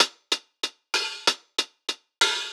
Index of /musicradar/ultimate-hihat-samples/95bpm
UHH_AcoustiHatA_95-02.wav